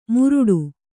♪ muruḍu